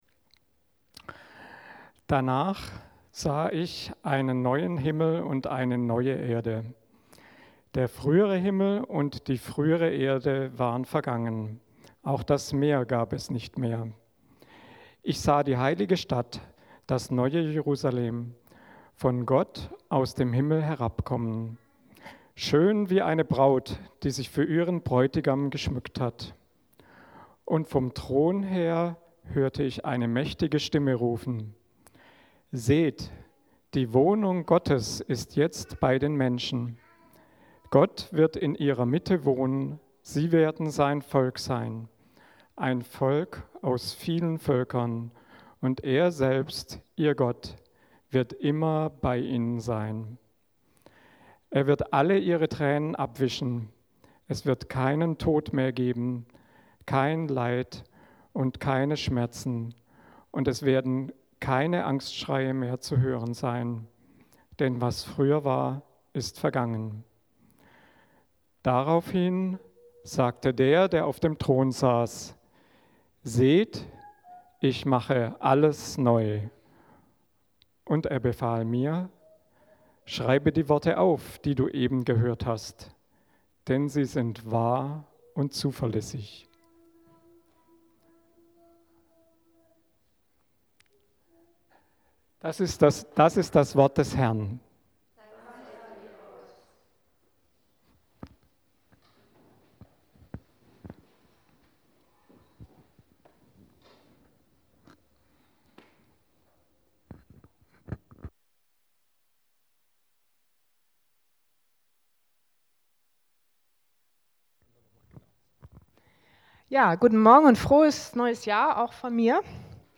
Predigt zur Jahreslosung 2026! | LIFE Berlin